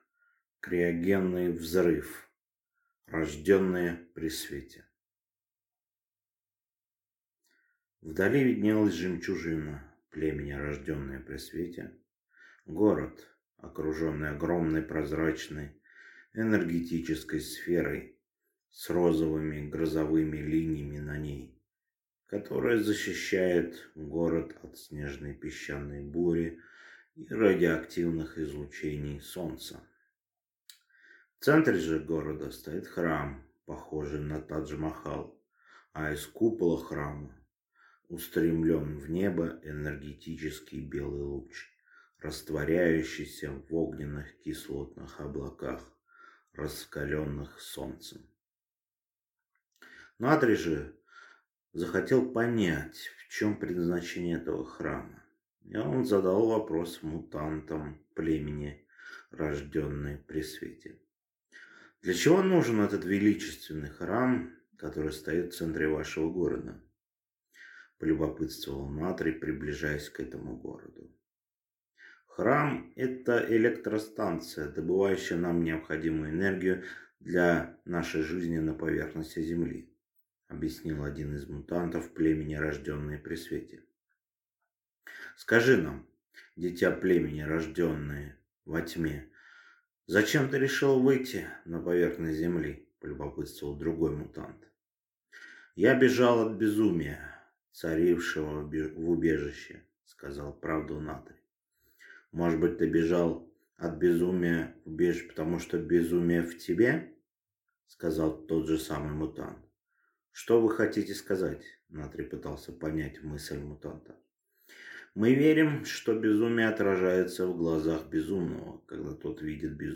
Аудиокнига Криогенный взрыв. Рождённые при свете | Библиотека аудиокниг